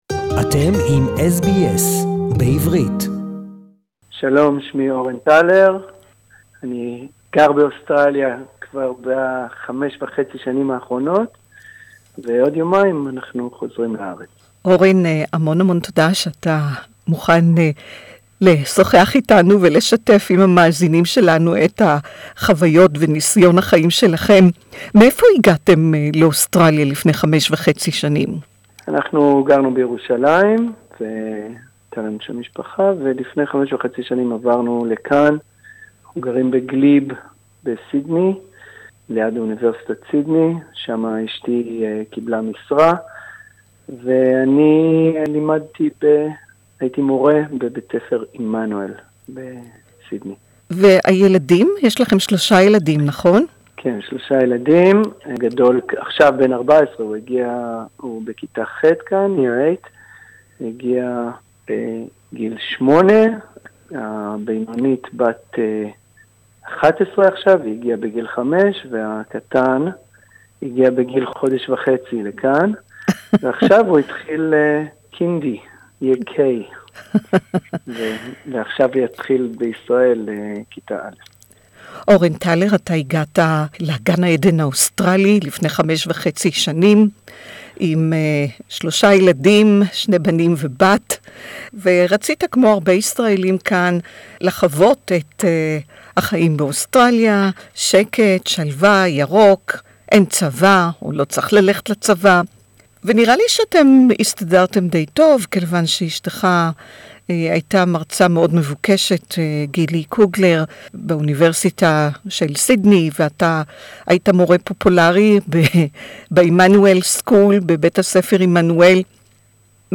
This interview is in Hebrew